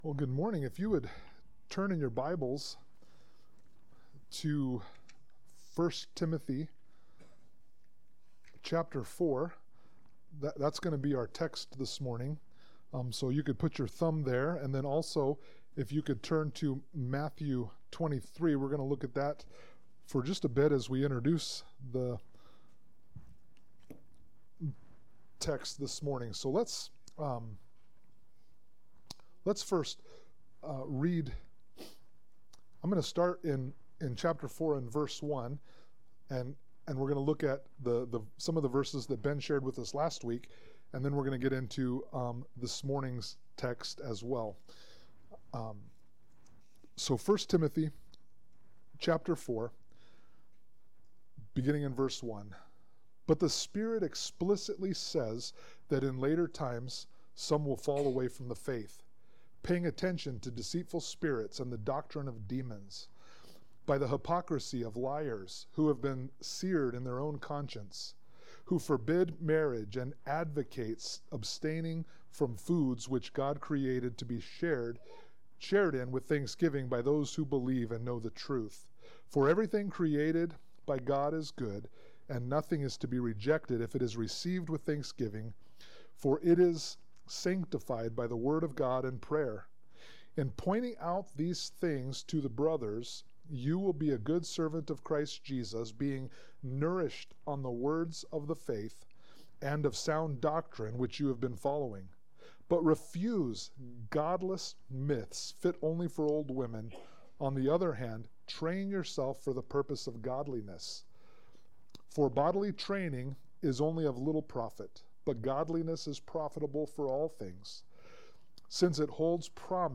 Sermon-9_28_25.mp3